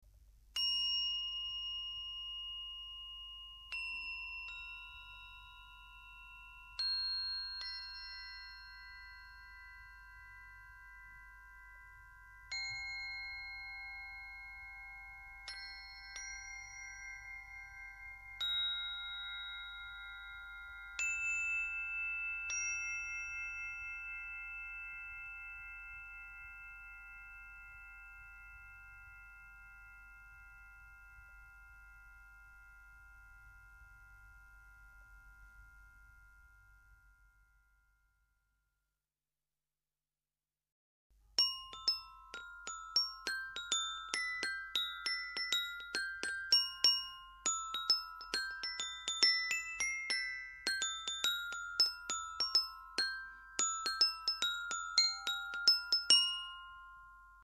縦型チャイム